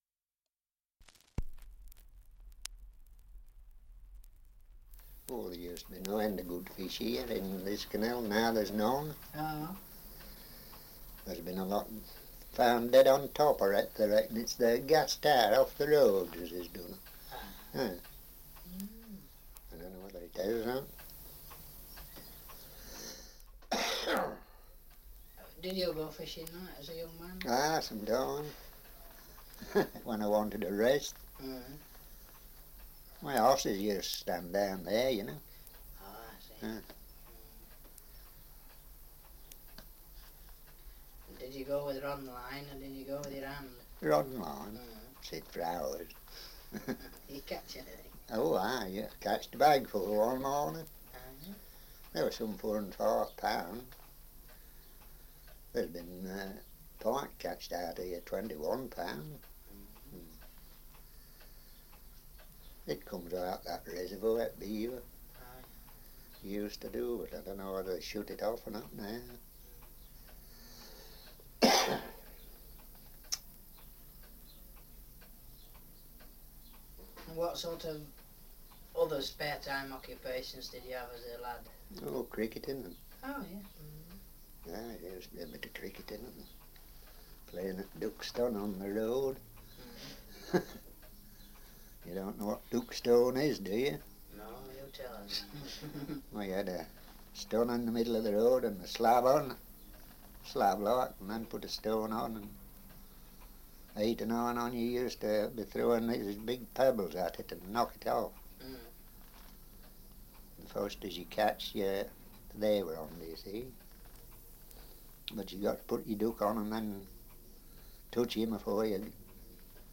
Survey of English Dialects recording in Great Dalby, Leicestershire
78 r.p.m., cellulose nitrate on aluminium